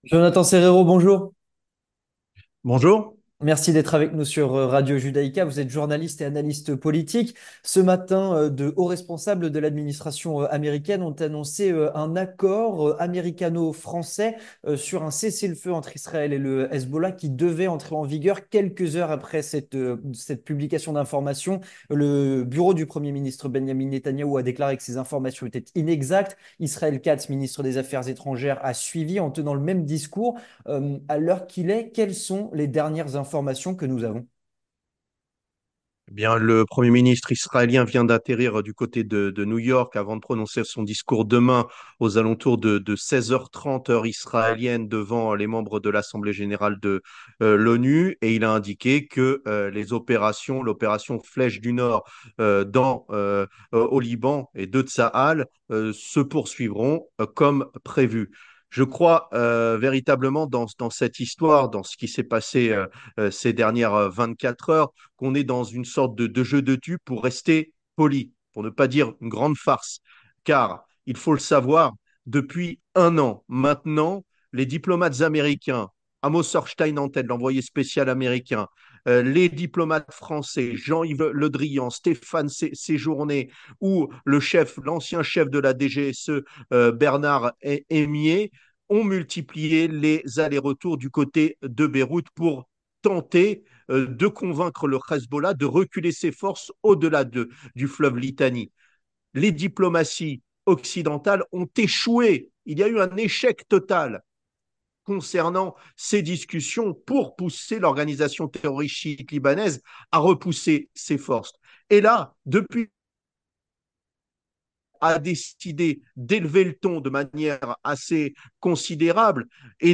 journaliste et analyste politique